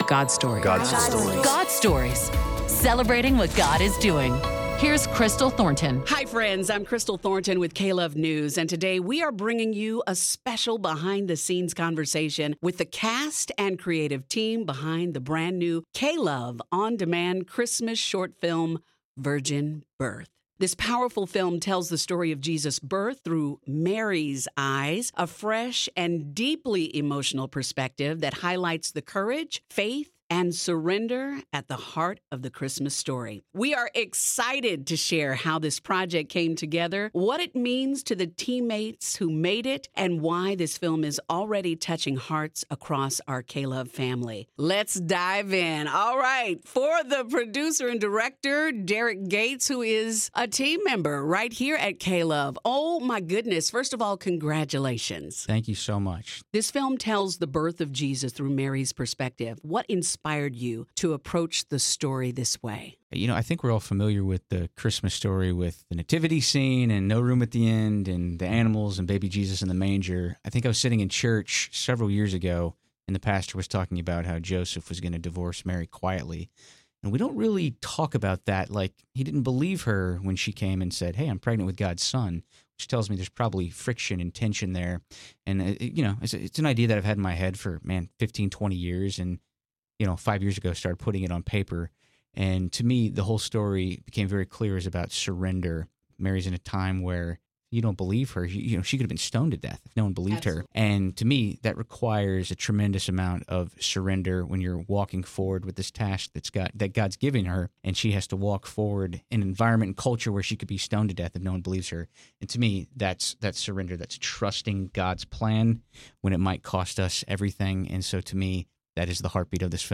Listen to the full behind-the-scenes conversation with the cast and creative team in the podcast below.